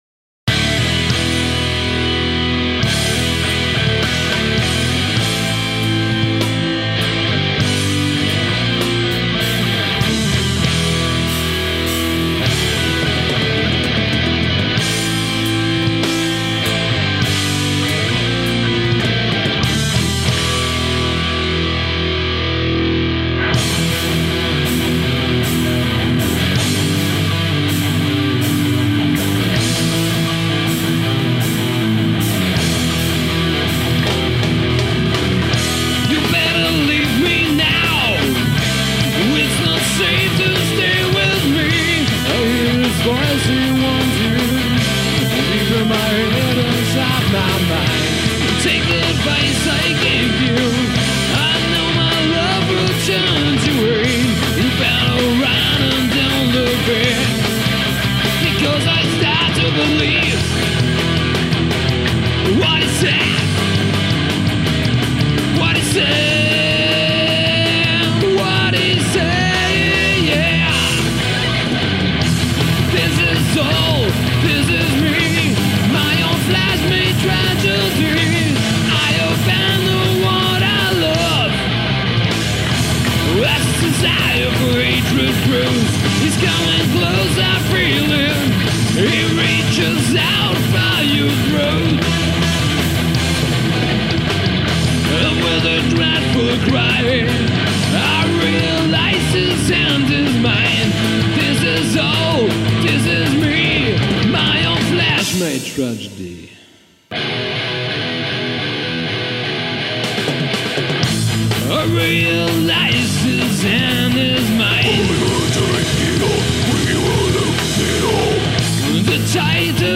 Gesang
Gitarre
Bass
Drums